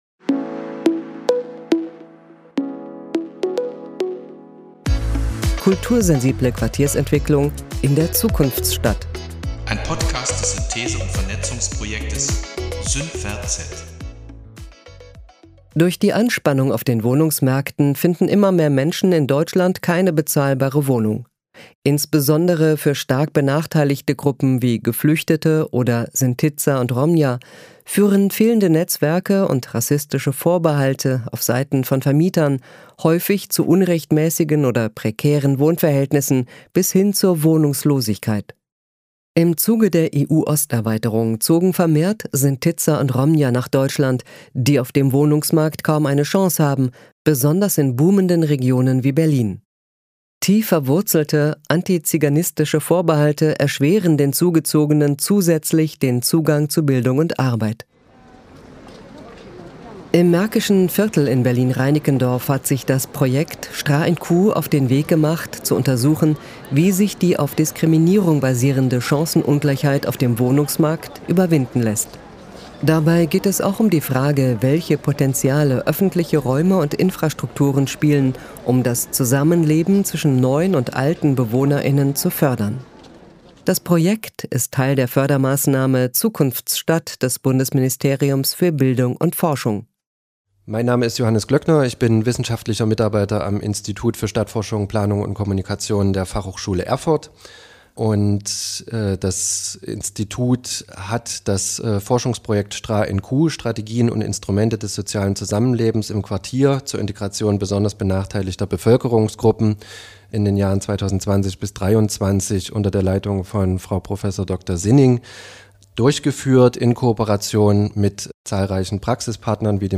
SynVer*Z hat mit verschiedenen Akteur*innen gesprochen, die am Projekt beteiligt waren